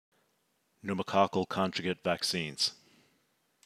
Pronounce: